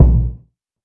Waka Kick 2 (6).wav